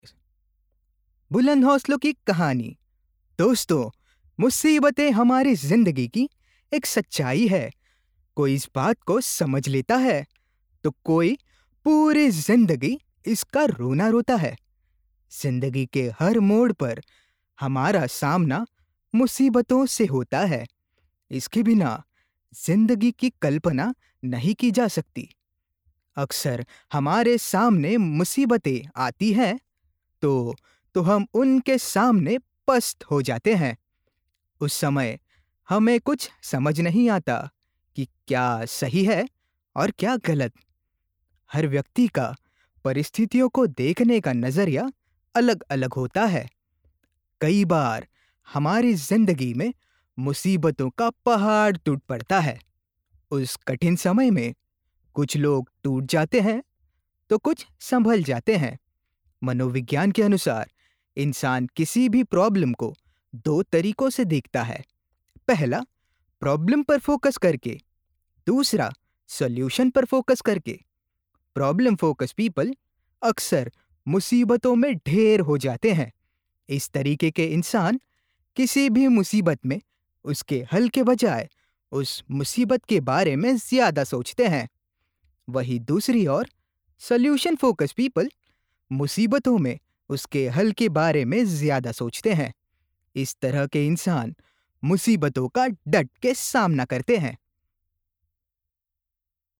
Native speaker Male 30-50 lat
Nagranie lektorskie
motivational.mp3